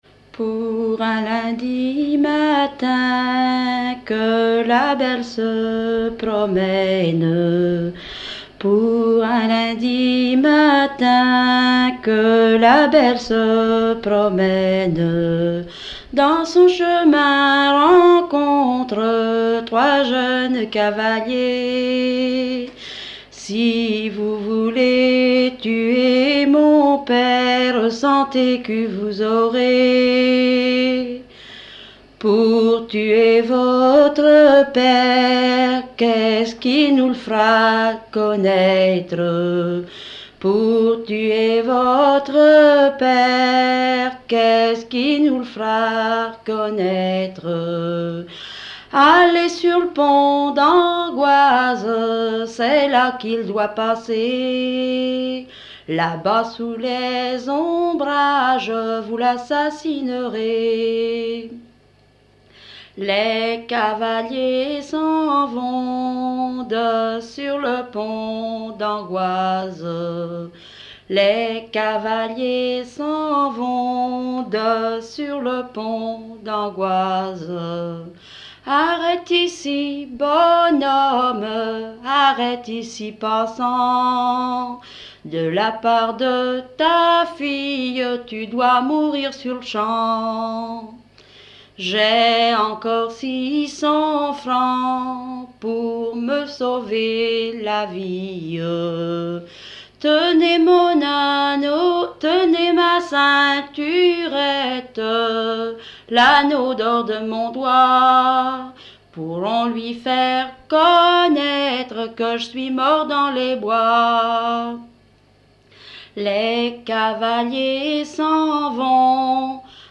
Localisation Barbâtre
Genre strophique
Pièce musicale inédite